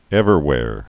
(ĕvər-wâr, -hwâr)